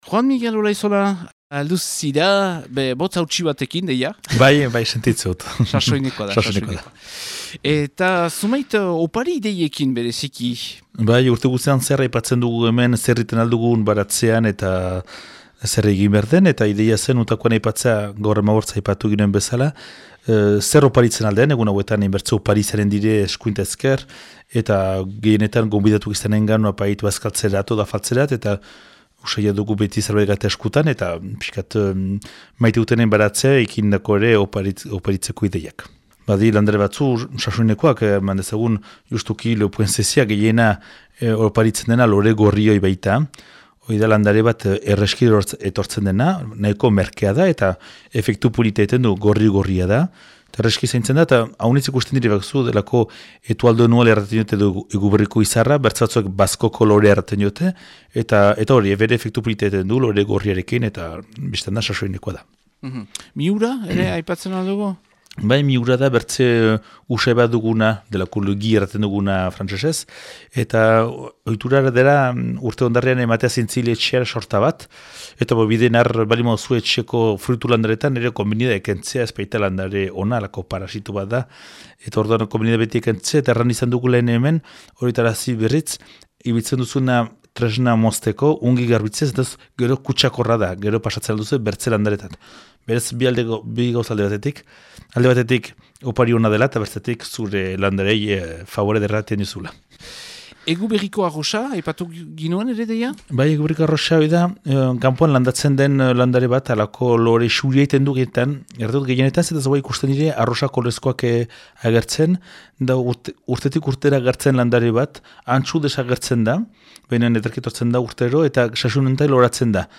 bi astero gurekin zuzenean asteazkenez. Eguberri garaiko gaia hautatu aldi honetan, opariak.